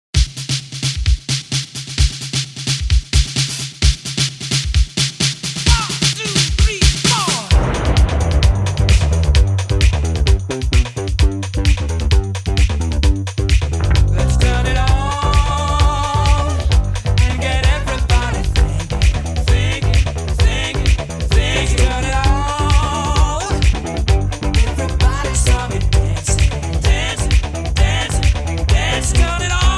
Pop Rock, Synth-Pop, Disco, Pop